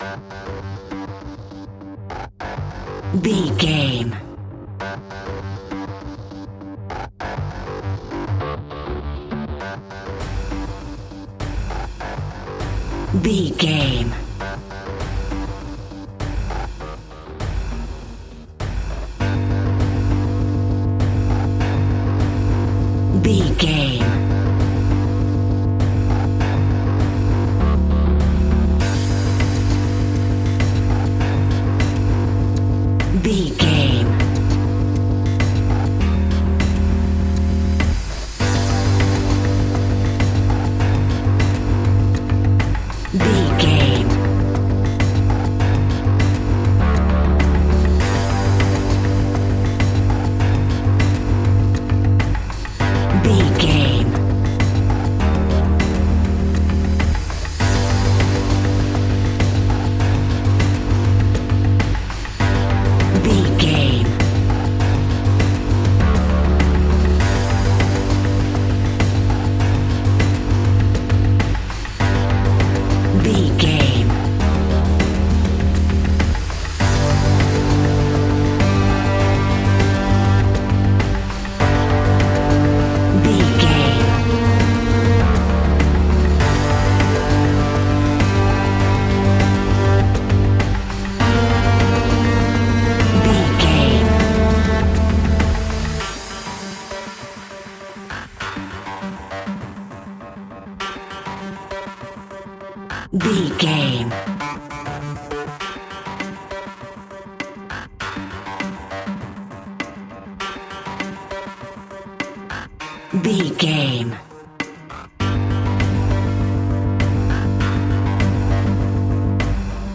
Aeolian/Minor
scary
ominous
dark
haunting
eerie
synthesiser
percussion
drums
bass guitar
strings
ticking
electronic music